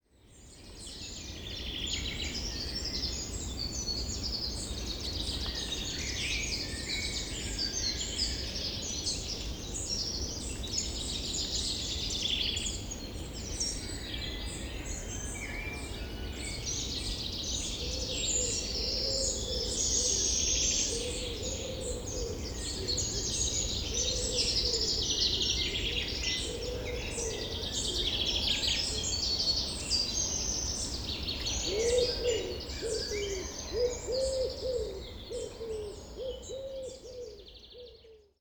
forestsound.wav